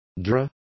Complete with pronunciation of the translation of juror.